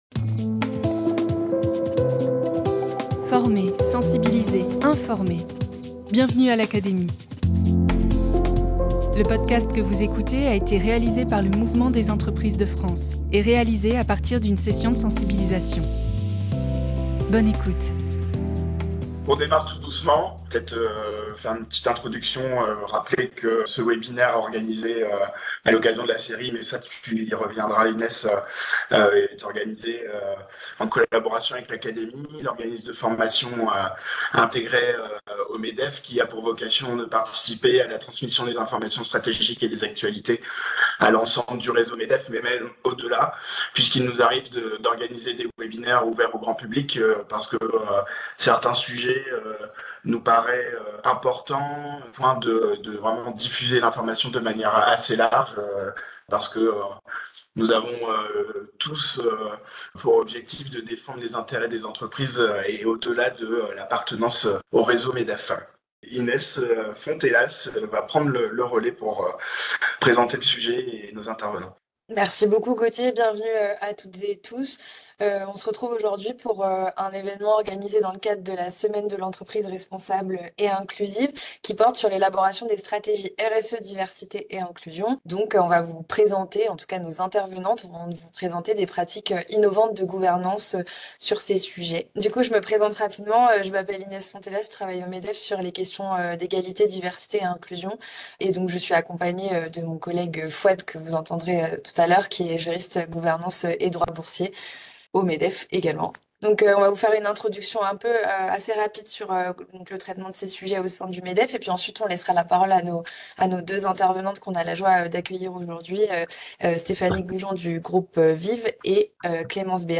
Dans ce podcast, nous vous présentons des pratiques d’entreprises innovantes relatives à la gouvernance des stratégies RSE & D&I. Ce podcast est issu d’un webinaire organisé dans le cadre de la semaine de l’entreprise responsable et inclusive qui a eu lieu au MEDEF en octobre 2024.